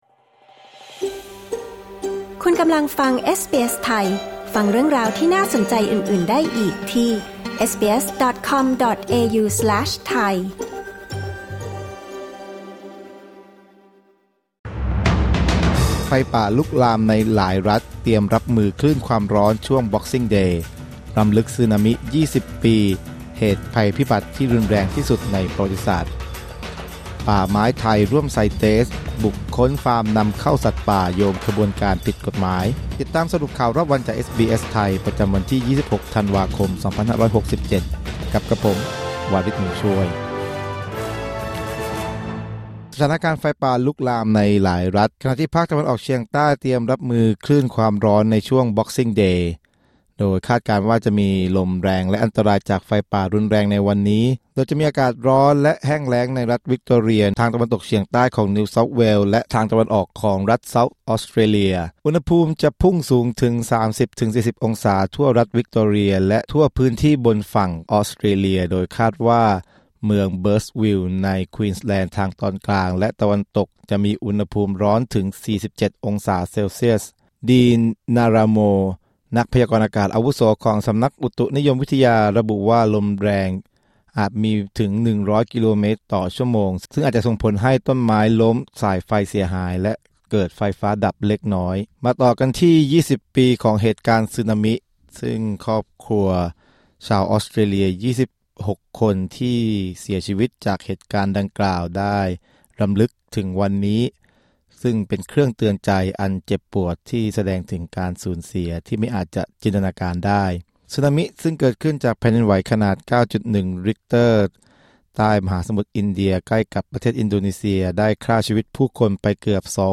สรุปข่าวรอบวัน 26 ธันวาคม 2567